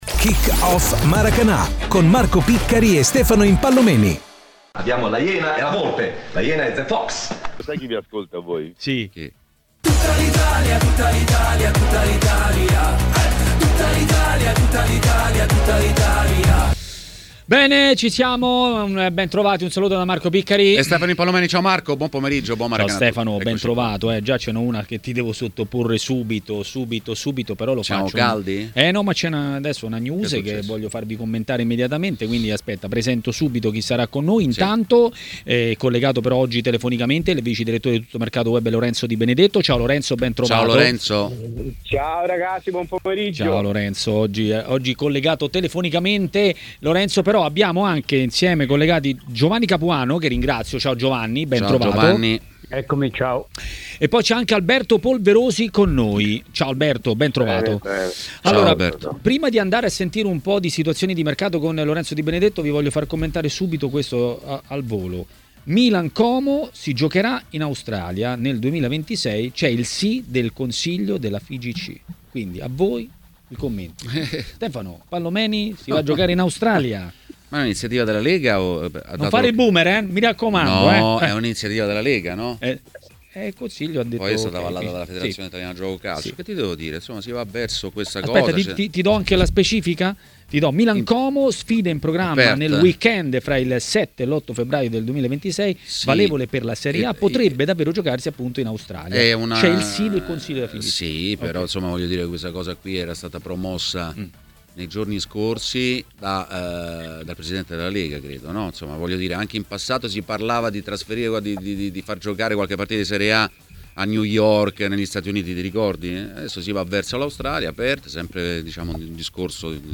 ha parlato dei movimenti in Serie A a TMW Radio, durante Maracanà.